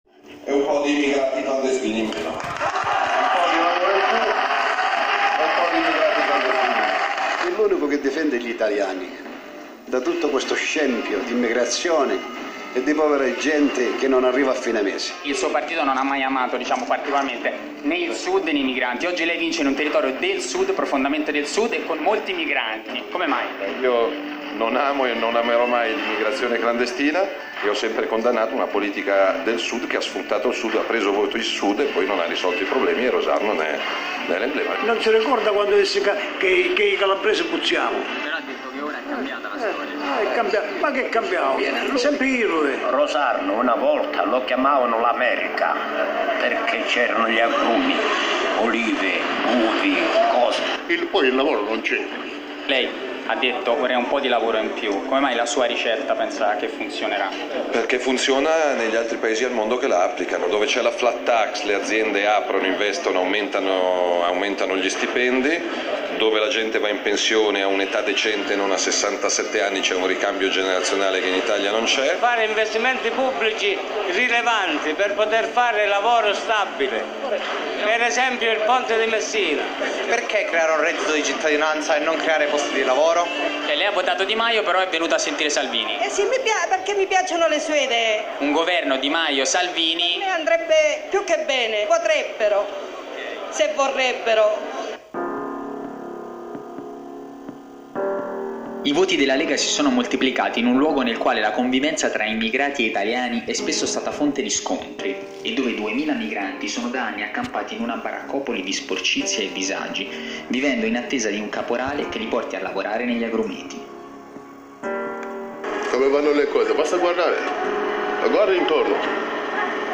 Rosarno (RC): le dichiarazioni di Matteo Salvini, intervenuto ad un convegno tenutosi sabato in Calabria [Audio]
UNA VISITA DURANTE LA QUALE, IL MASSIMO RESPONSABILE DELLA LEGA HA RICEVUTO UNA FESTOSA ACCOGLIENZA